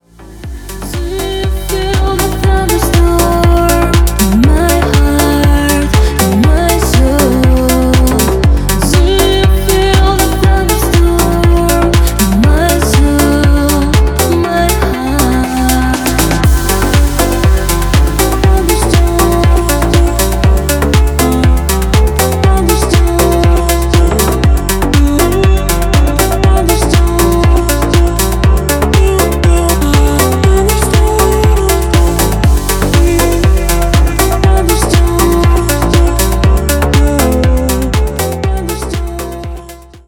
• Качество: 320, Stereo
громкие
deep house
house
нежные